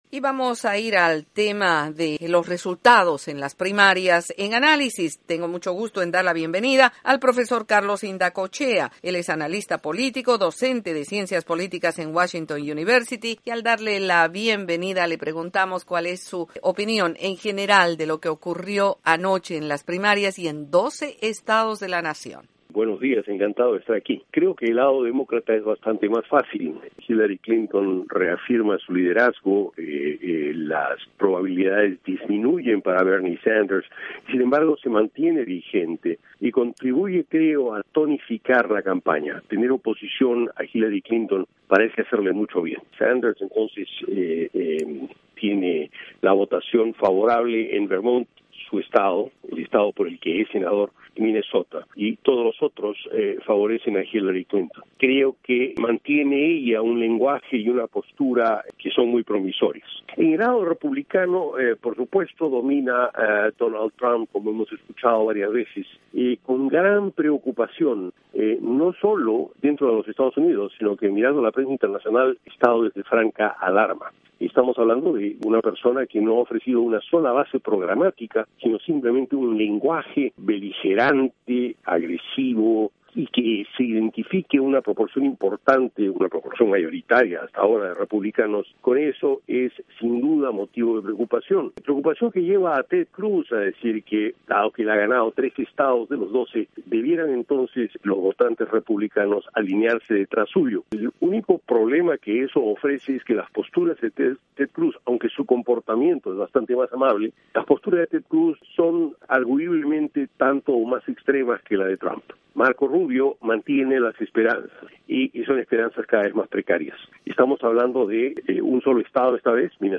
Entrevista con el analista político